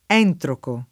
entroco [ $ ntroko ] s. m. (geol.); pl. ‑chi